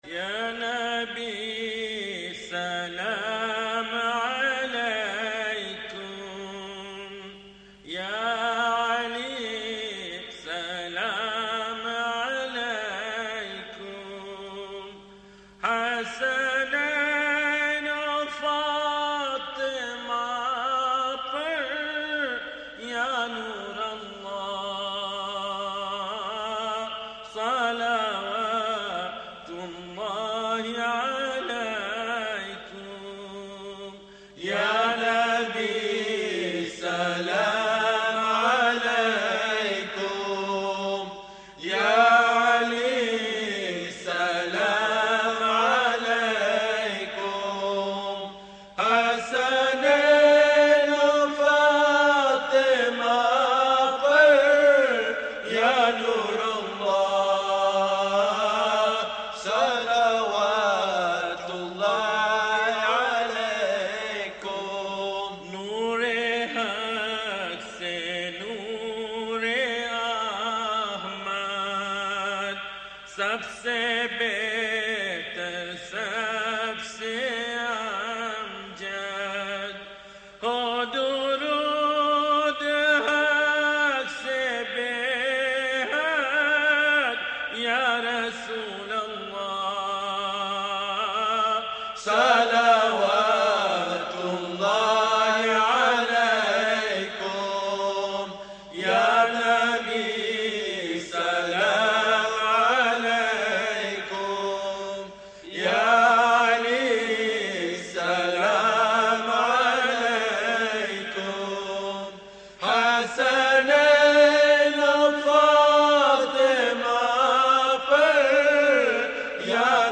Non Saff Classic Munajaats